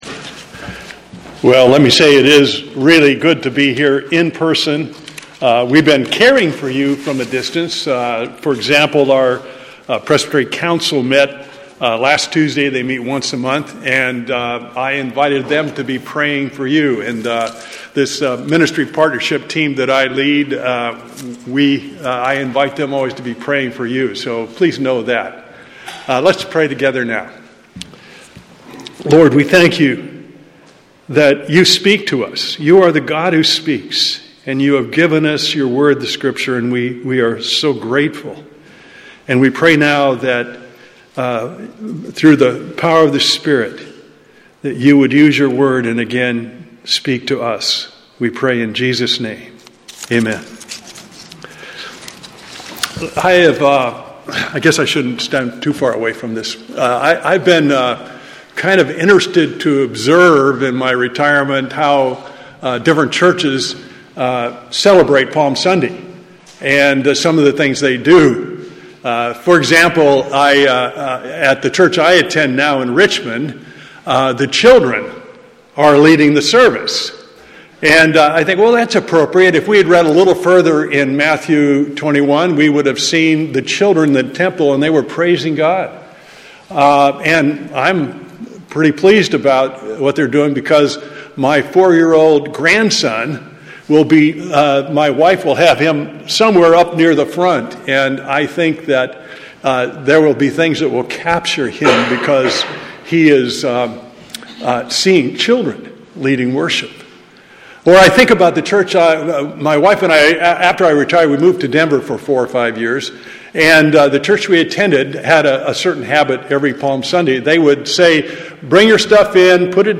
This Palm Sunday sermon is shared by our guest speaker